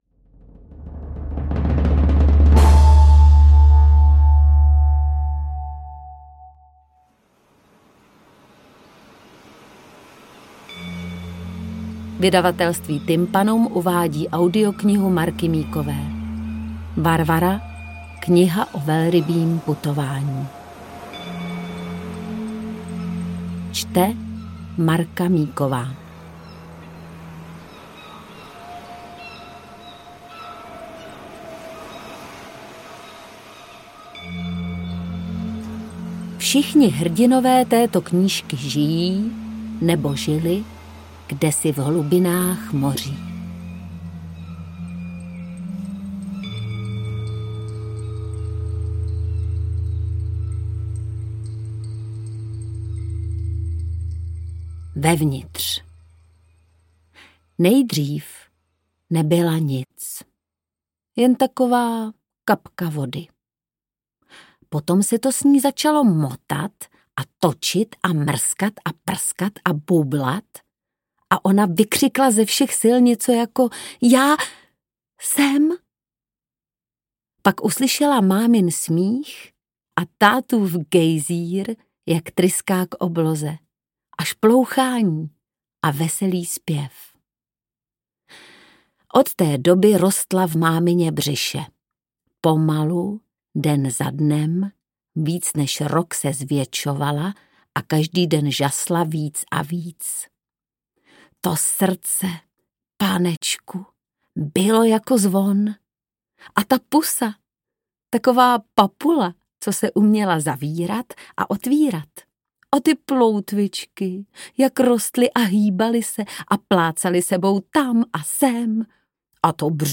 Interpret:  Marka Míková